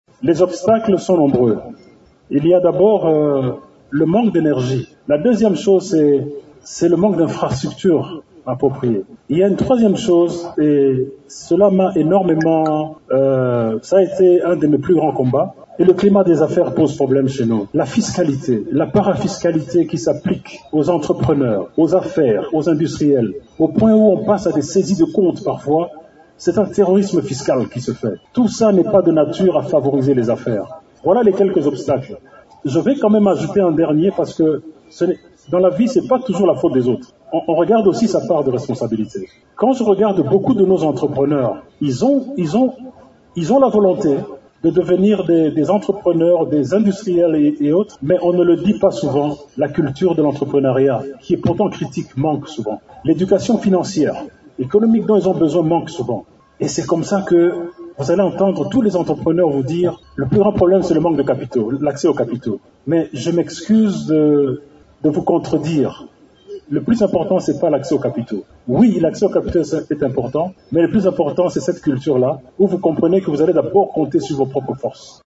Vous pouvez écouter ici le ministre de l’Industrie :